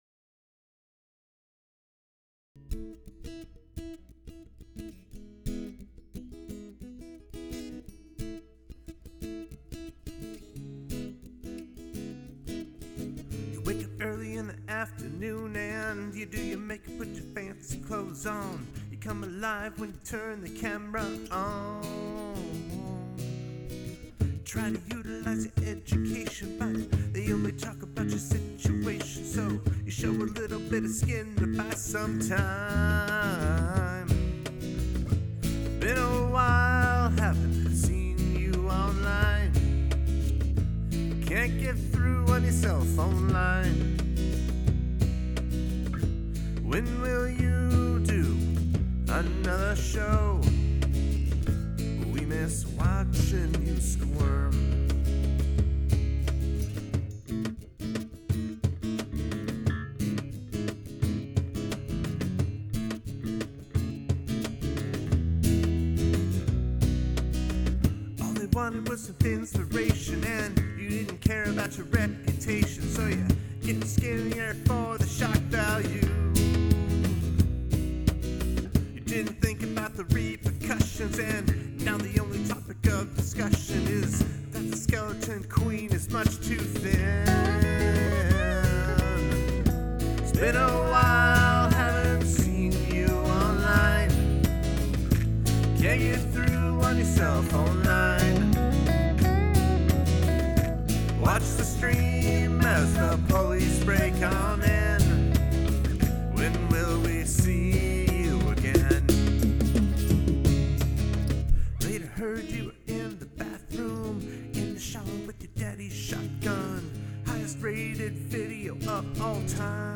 Make a song with quiet and loud parts -- like every Pixies song you love.
capo iv
I’m not keen on the staccato vocal at the beginning and in parts of the verses.
The recording seems lacking in fullness, but I like the songwriting and most of your vocals. The way you sing the backing vocals is a nice contrast to the main vocal.
I like the bass line.
That DI acoustic sound is too sterile for me.